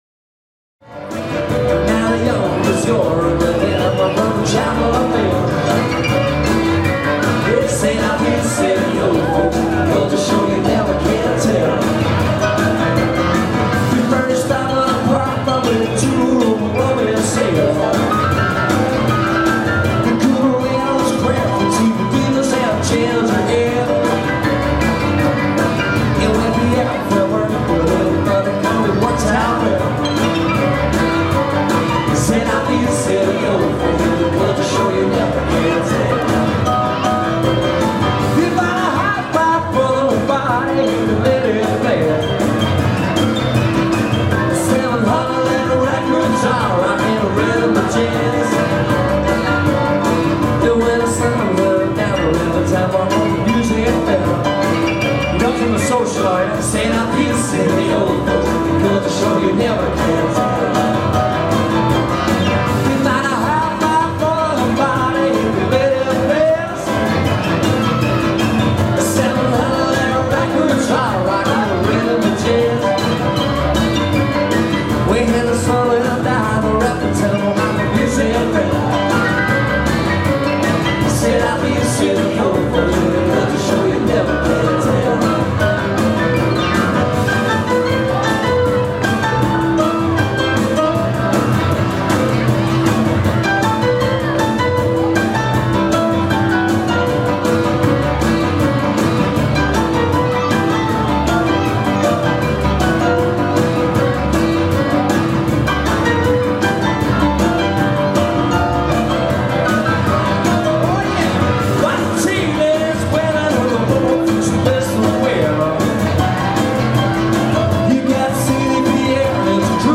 Unique & Talented Piano/Vocal Duo